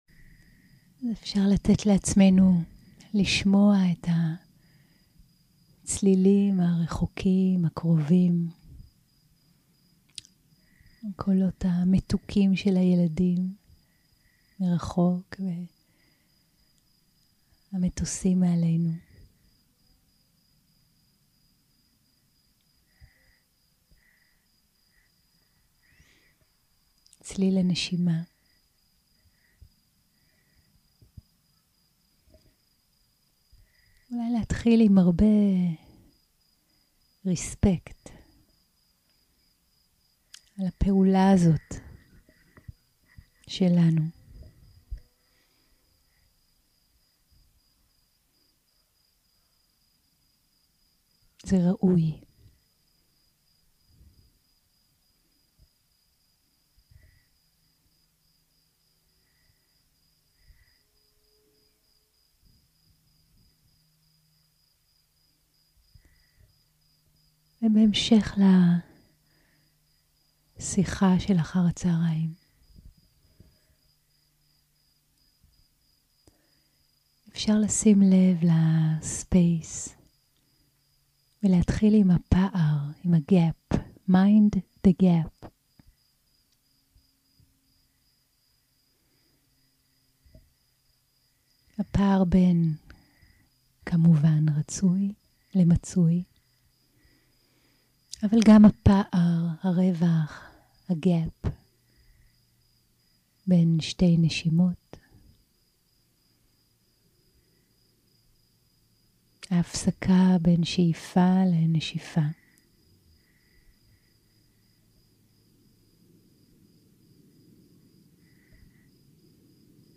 יום 3 - הקלטה 7 - ערב - הנחיות למדיטציה - מדיטציה ולימוד של מרחב גדול 0:00 0:00 סוג ההקלטה: סוג ההקלטה: שיחת הנחיות למדיטציה שפת ההקלטה: שפת ההקלטה: עברית